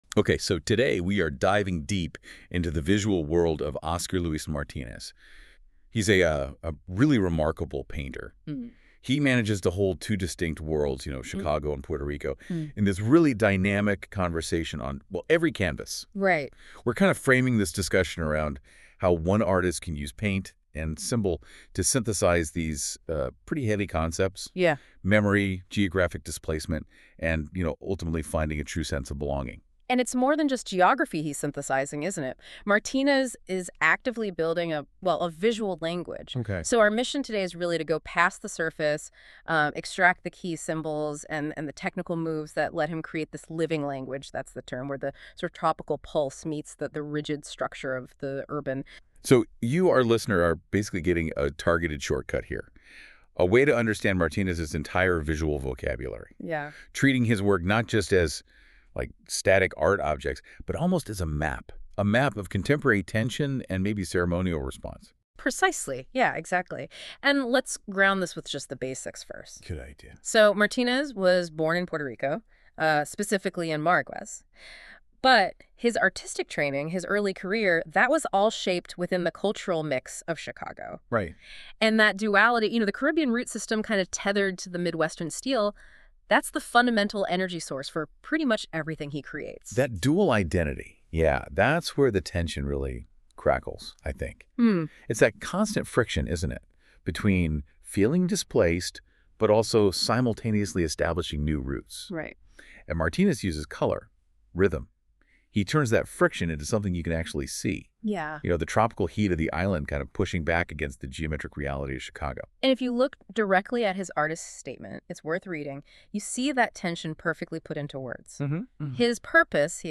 A field recording and narrative mapping piece exploring urban soundscapes, memory routes, and belonging.